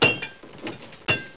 1 channel
weapons_workshop.wav